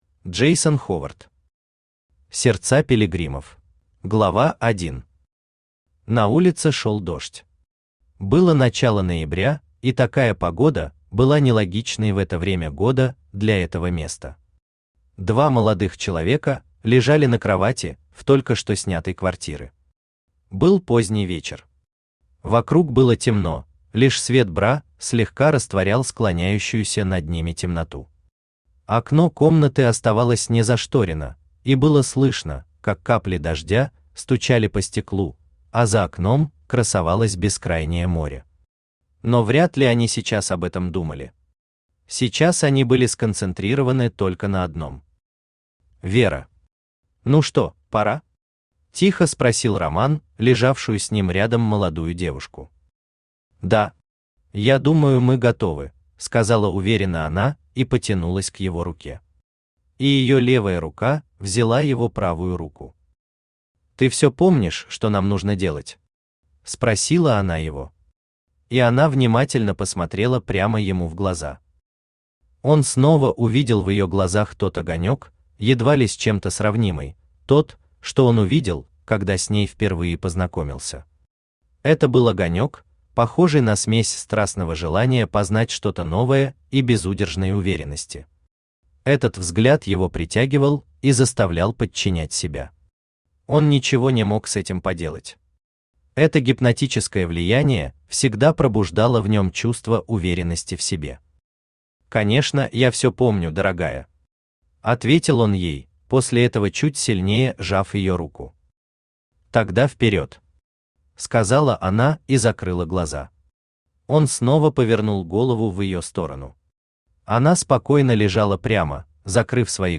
Aудиокнига Сердца пилигримов Автор Джейсон Энтони Ховард Читает аудиокнигу Авточтец ЛитРес. Прослушать и бесплатно скачать фрагмент аудиокниги